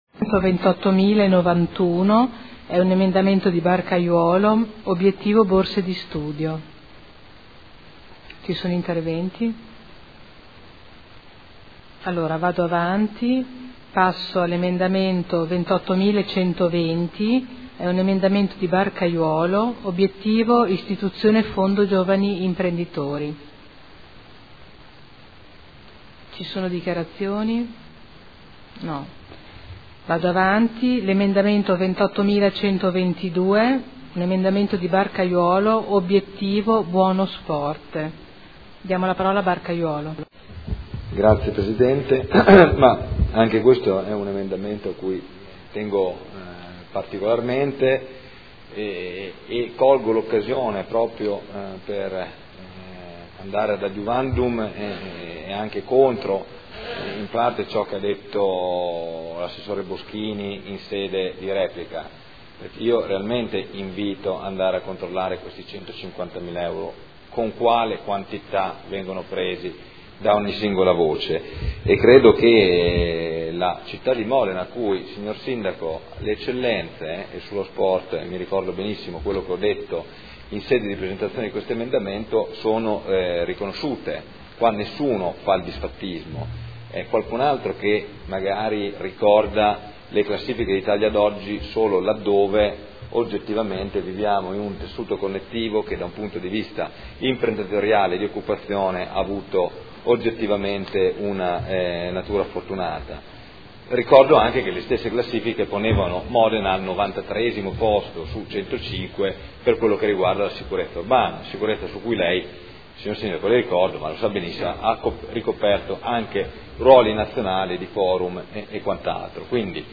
Seduta del 13 marzo. Dichiarazioni di voto su singoli emendamenti o complessive